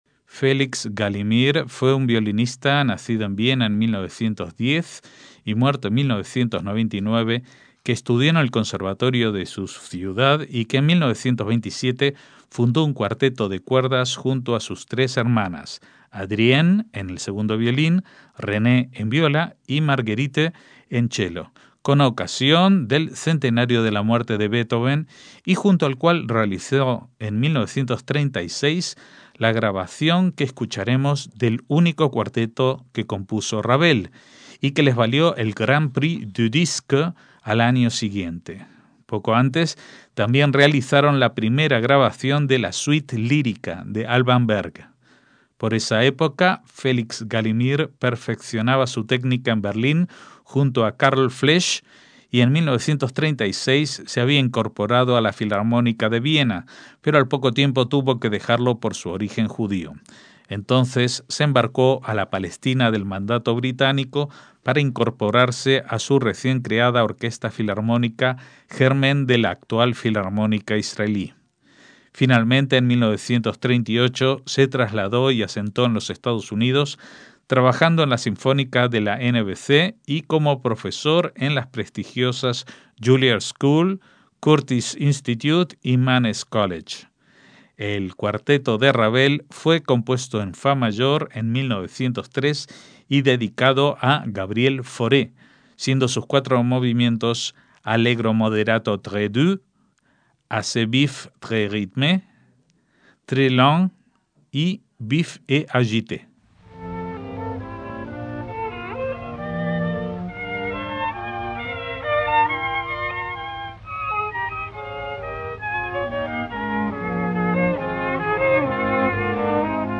MÚSICA CLÁSICA
cuarteto de cuerdas